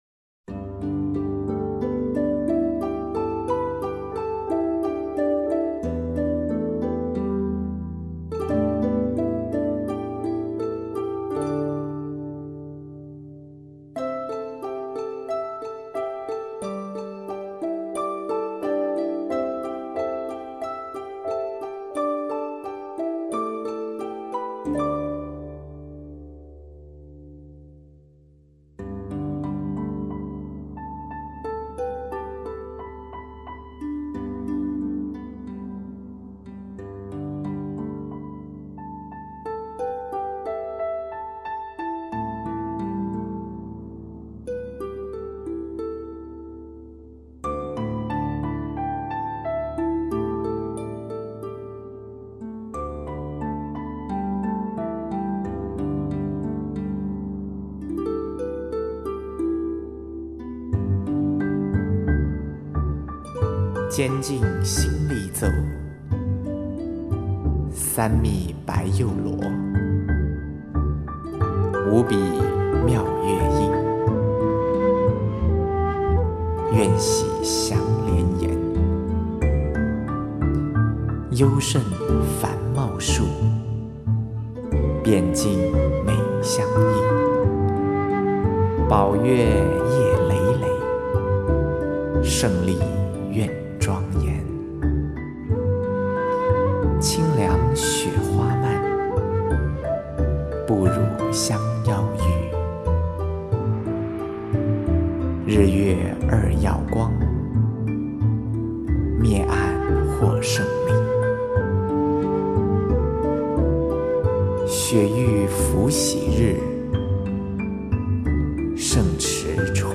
采用藏乐、中乐、西乐兼融并蓄的跨界音乐制作手法
以及从藏腔到民谣风的男女人声表现，整体风格突出而大气